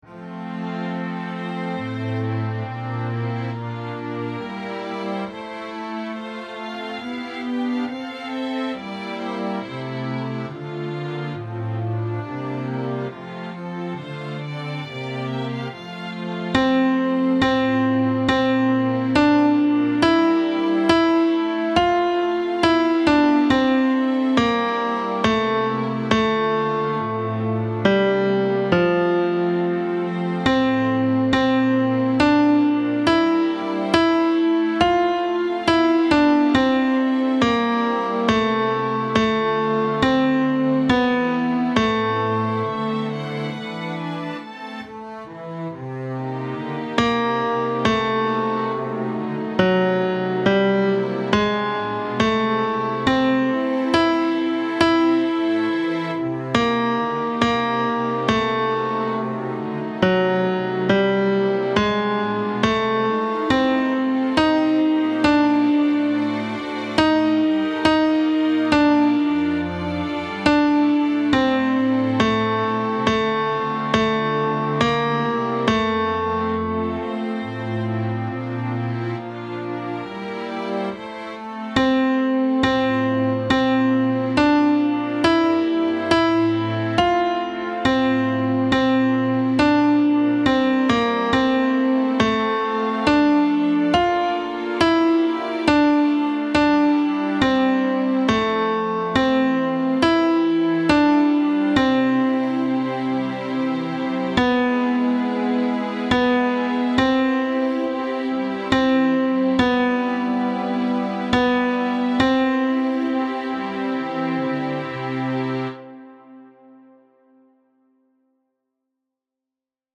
Tenor I
Mp3 Música